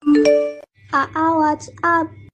Kategori: Nada dering
Keterangan: Ini adalah suara notifikasi yang lucu untuk WA.